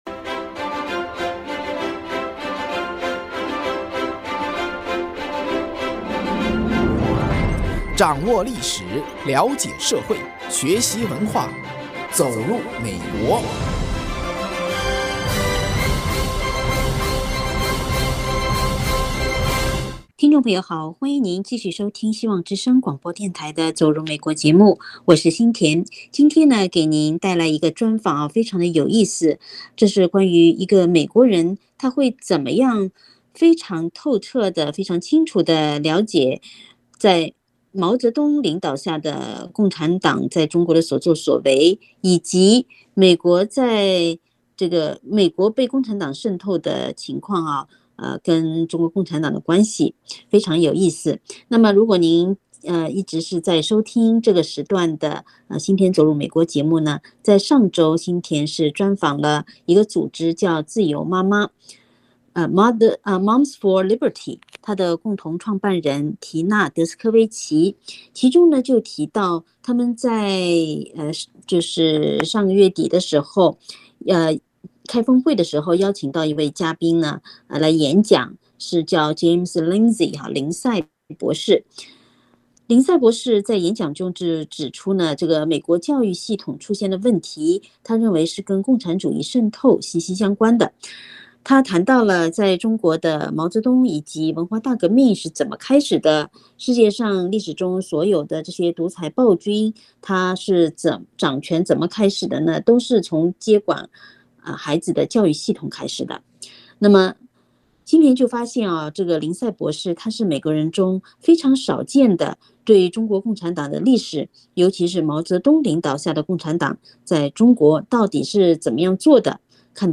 【希望之聲2023年7月20日】（主持人